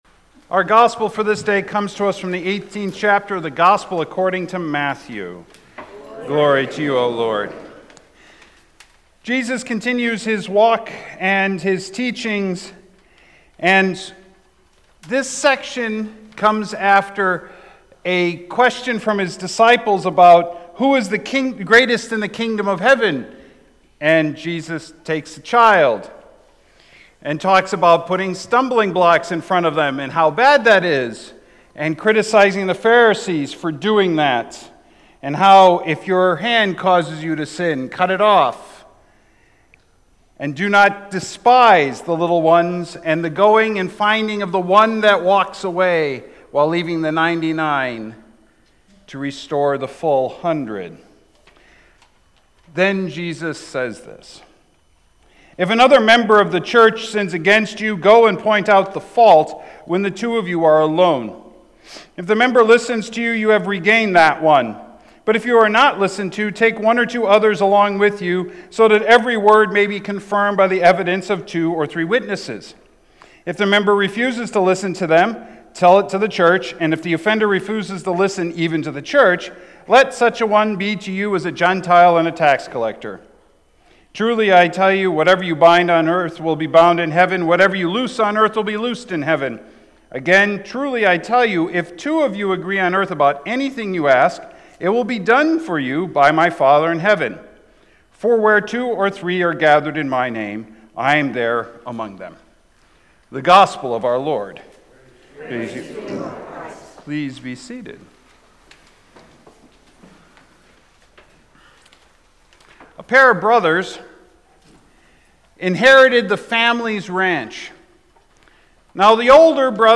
Sermons | Beautiful Savior Lutheran Church